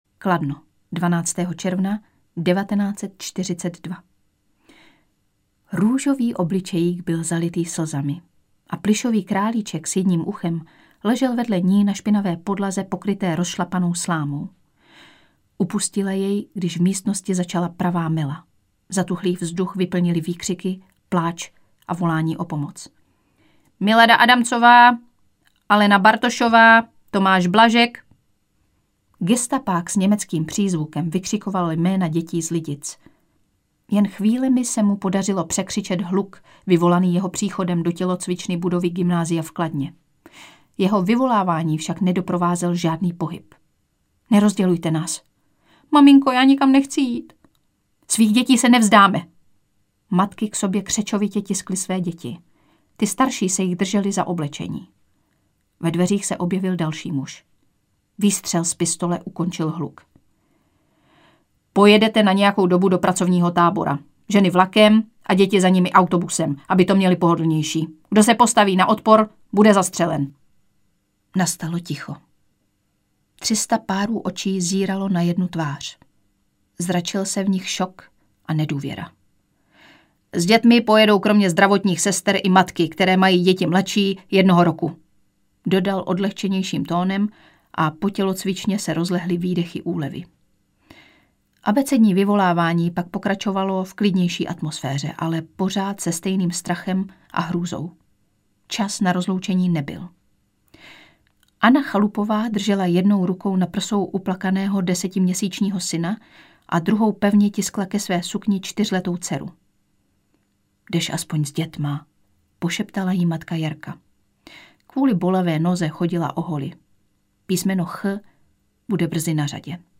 Audiobook
Read: Lucie Vondráčková